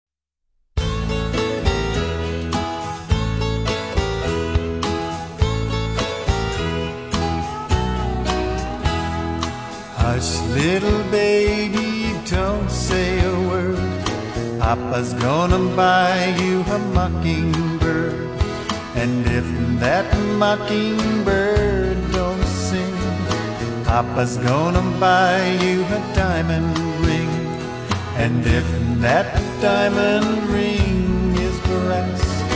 Home > Folk Songs